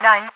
File usage The following page links to this file: Overwatch Voice/Quotes
Nine_ovoice.ogg